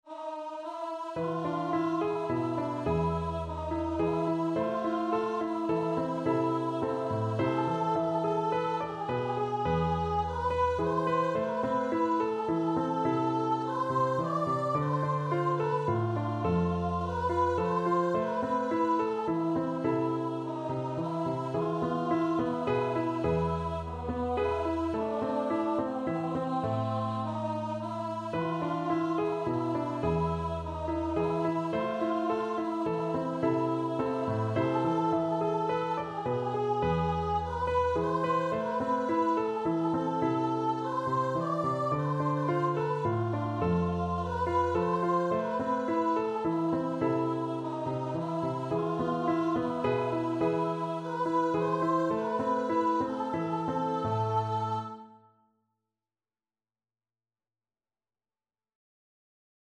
Voice
Slow Waltz = c. 106
3/4 (View more 3/4 Music)
A minor (Sounding Pitch) (View more A minor Music for Voice )
Traditional (View more Traditional Voice Music)